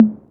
Teck-perc (tomtom).wav